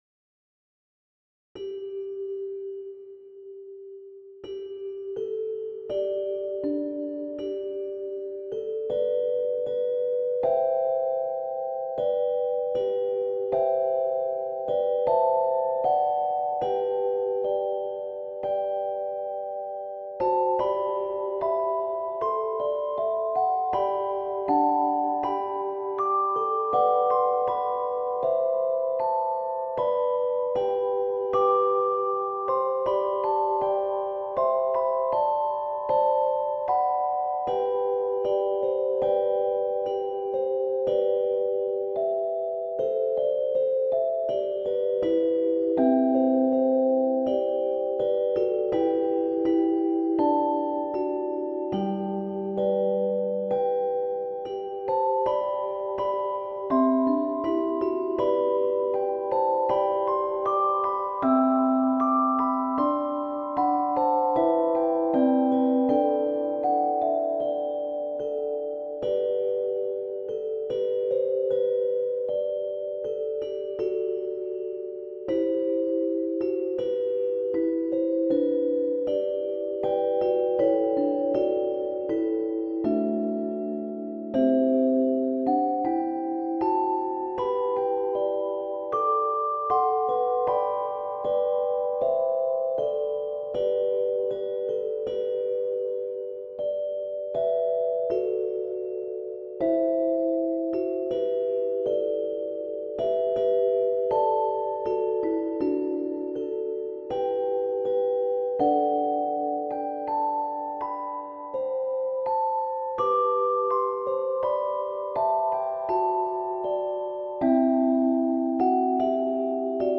習作